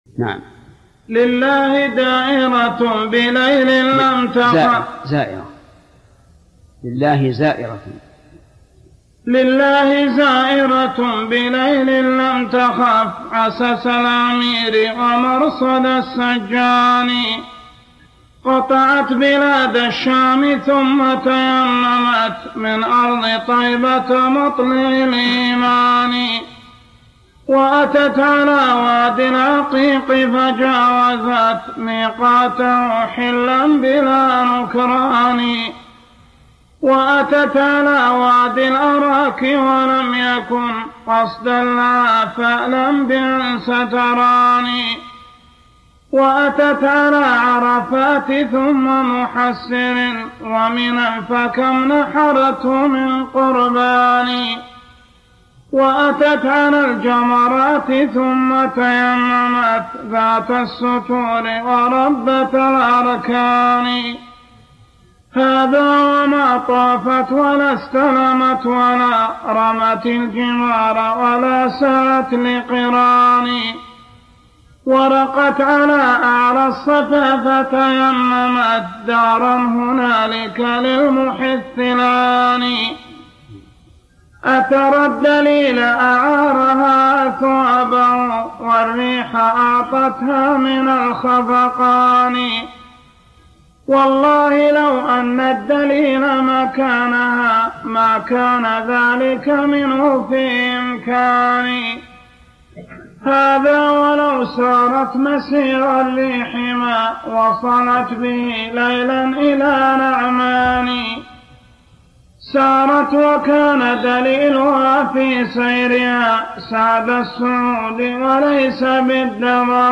ذكر زيارة المدينة ومكة لأداء المناسك والشوق للقاء الحبيب (قراءة للمتن) - ابن عثيمين